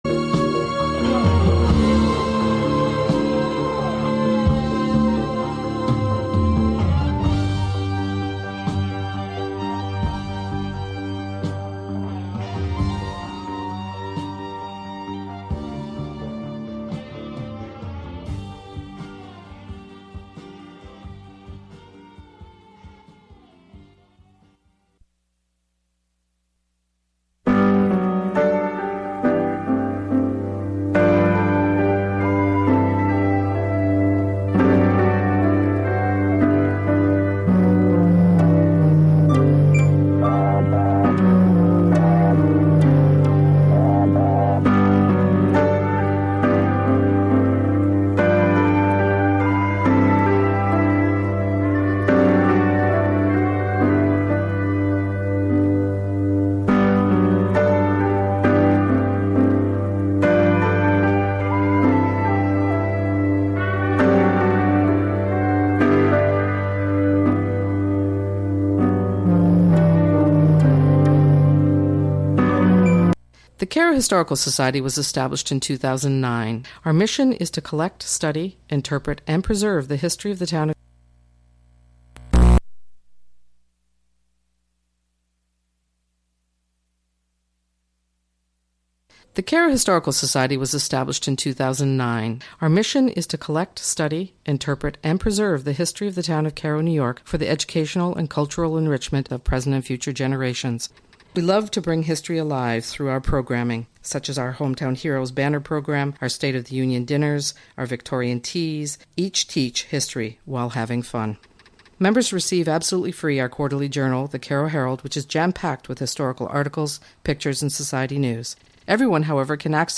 Broadcast live from the Carnegie Room of the Catskill Library.